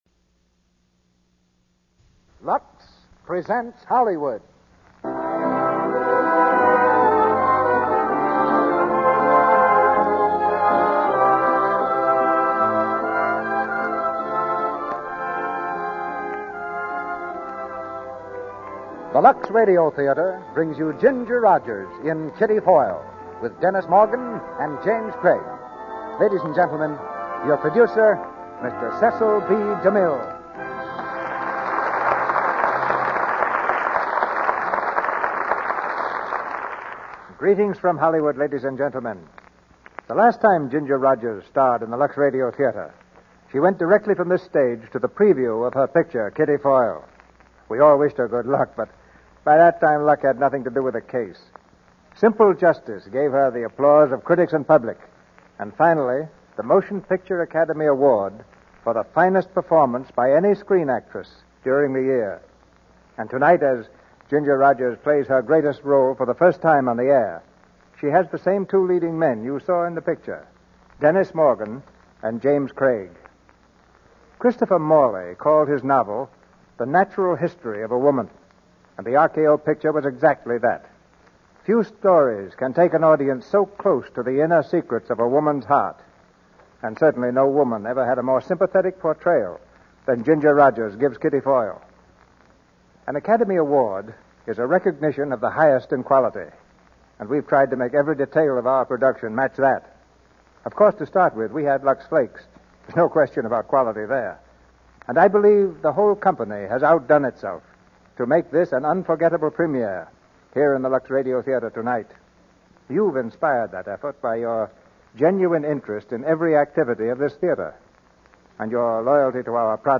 Kitty Foyle, starring Ginger Rogers, Dennis Morgan, James Craig